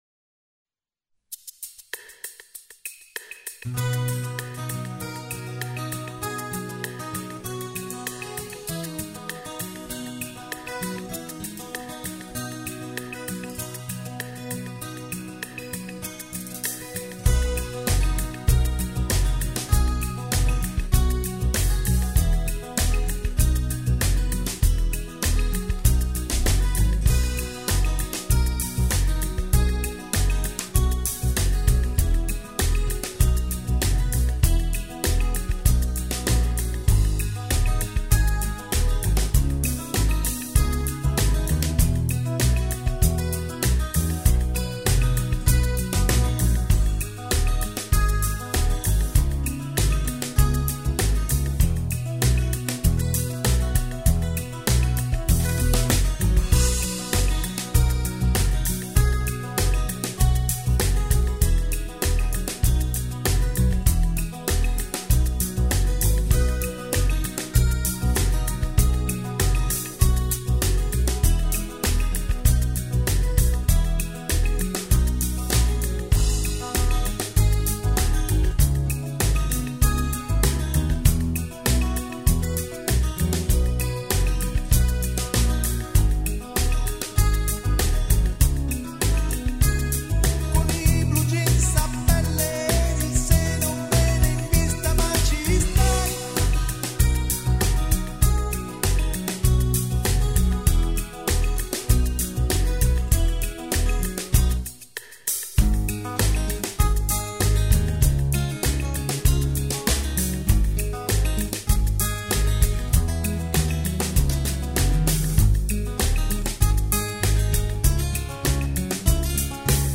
Basi